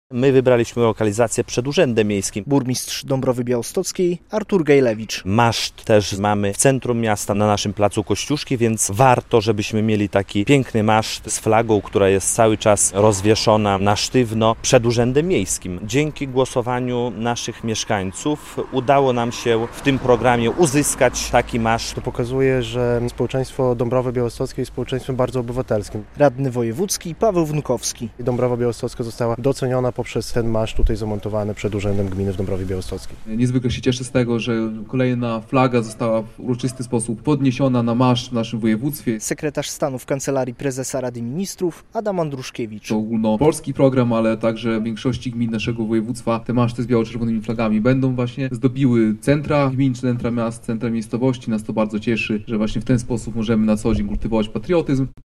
Maszt stanął przy urzędzie gminy.